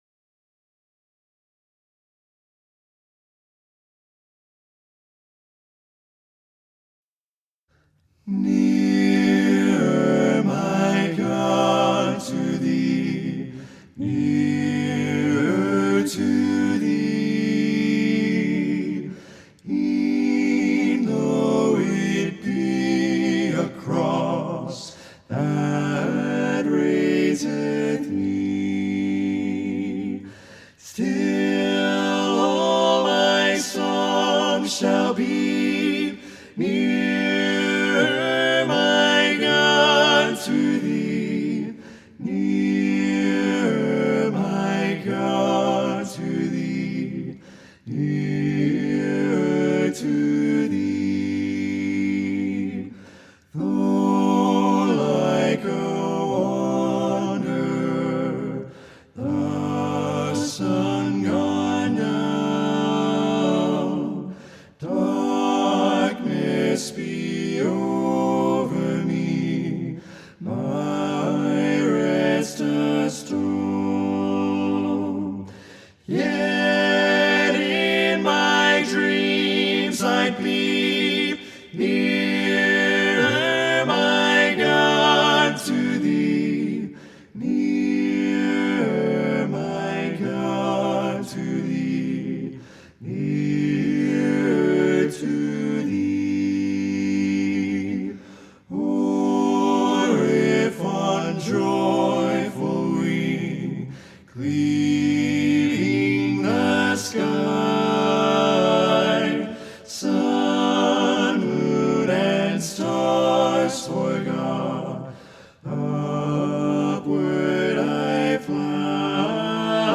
THESE ARE ALL THE SONGS WHICH WE HAVE PERFORMED OVER THE YEARS ALPHABETICALY